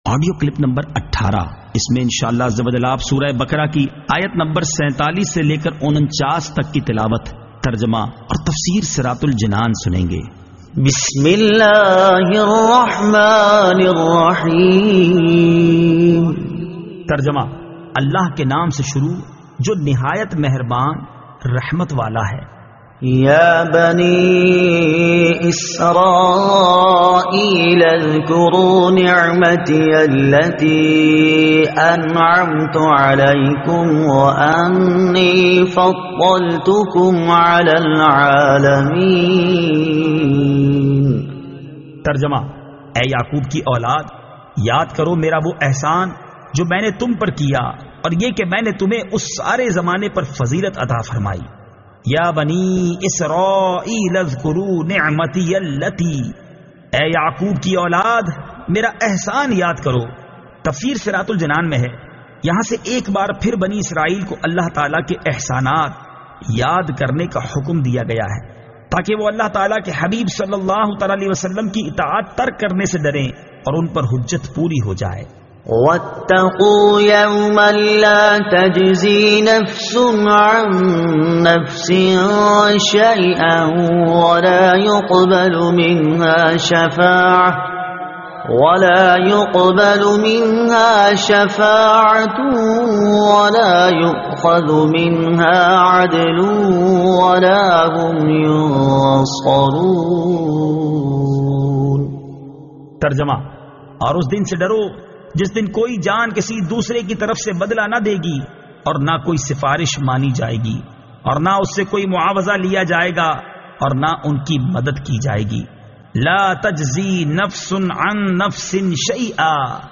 Surah Al-Baqara Ayat 47 To 49 Tilawat , Tarjuma , Tafseer